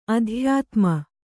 ♪ adhyātma